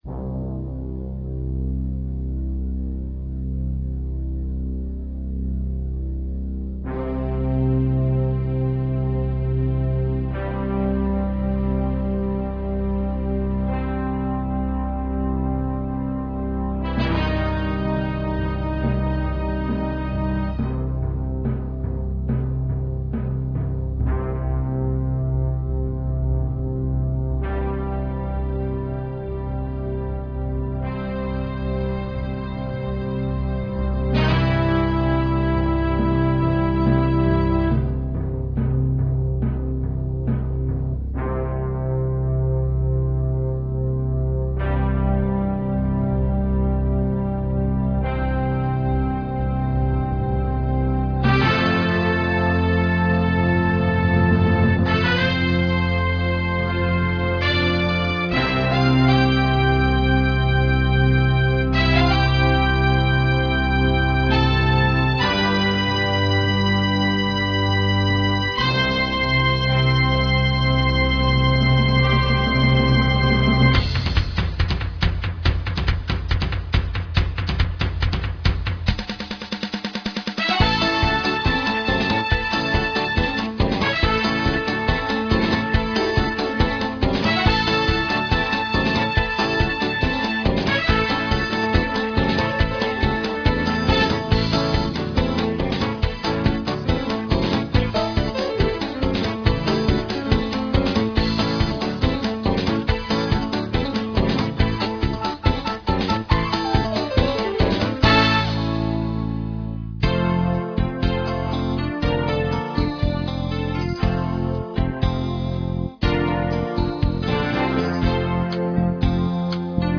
with the haunting sound of the pan pipes